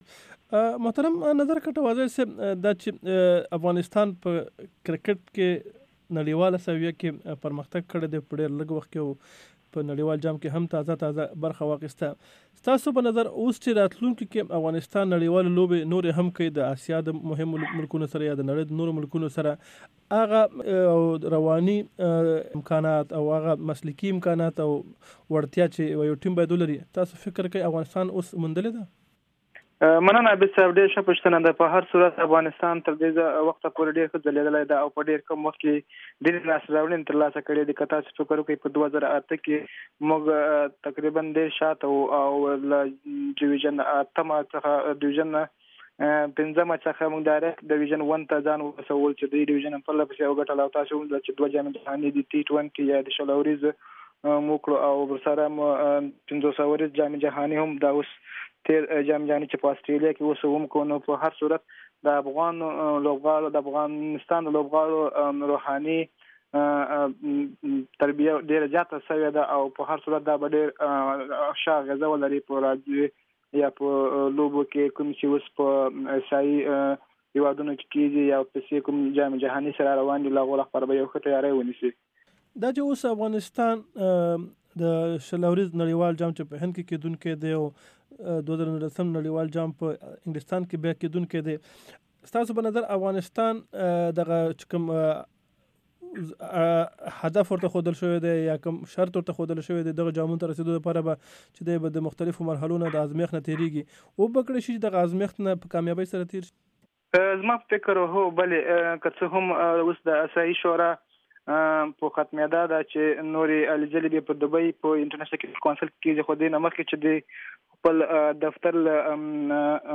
مرکې